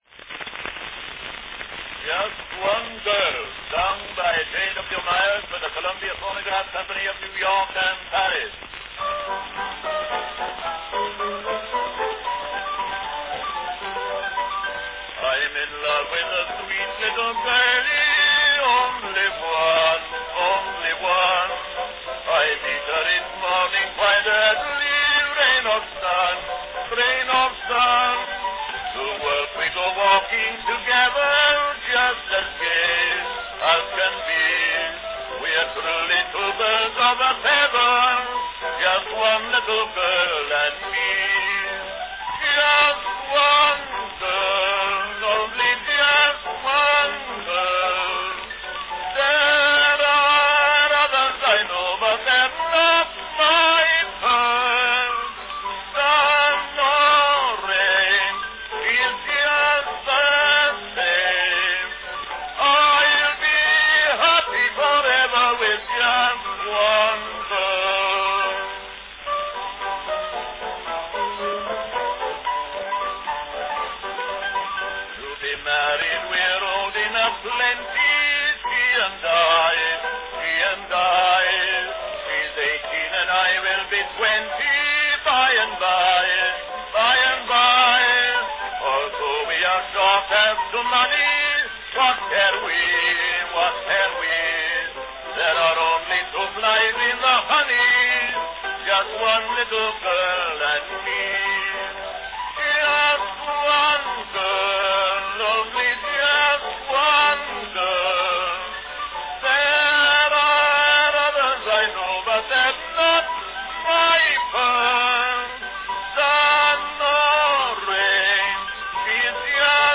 Cylinder of the Month
Category Baritone
Typical of the period, this recording carries the brash sound of a copy produced by pantograph from a loud 5" diameter master.